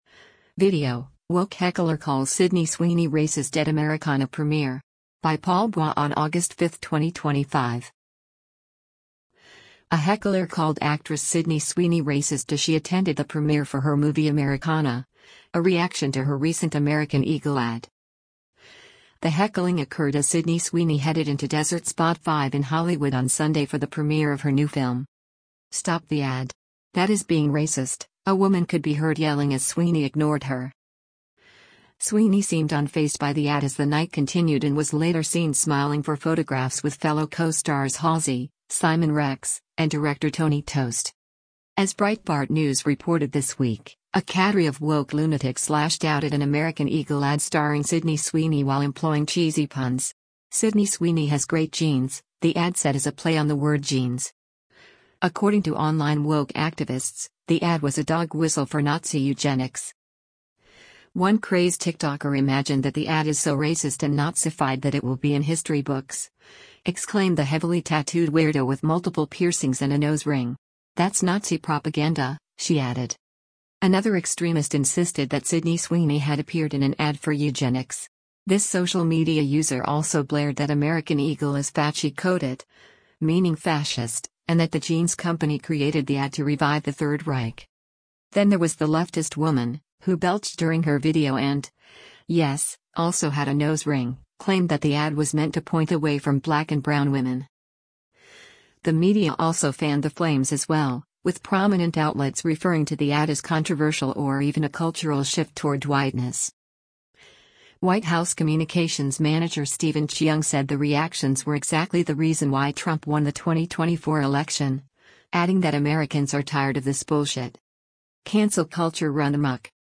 The heckling occurred as Sydney Sweeney headed into Desert Spot 5 in Hollywood on Sunday for the premiere of her new film.
“Stop the ad. That is being racist,” a woman could be heard yelling as Sweeney ignored her.